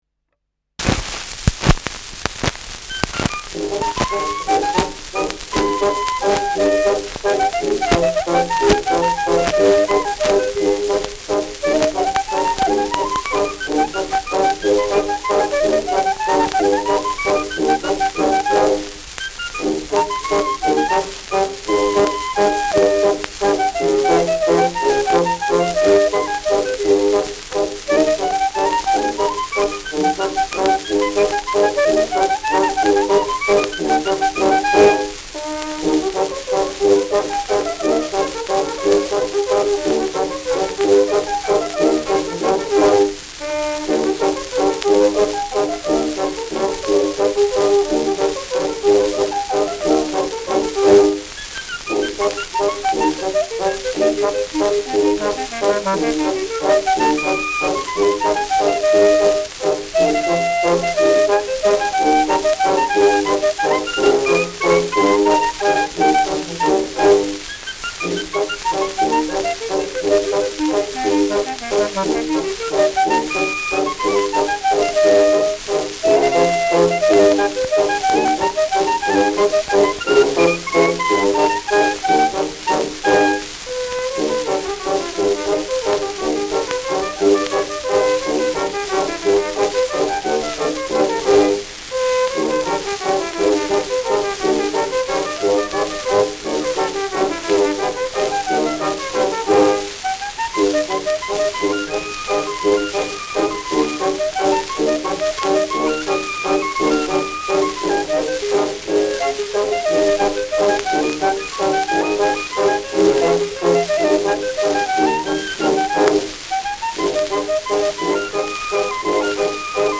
Schellackplatte
Knacken zu Beginn : präsentes Rauschen : präsentes Knistern : abgespielt : leiert : Nadelgeräusch : Tonnadel rutscht bei 2’11’’ über einige Rillen
Stadtkapelle Weißenburg (Interpretation)